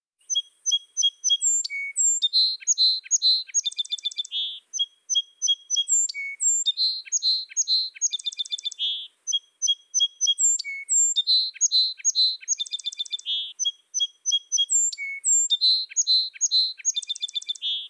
Song Sparrow:
1SPARROW-1.wav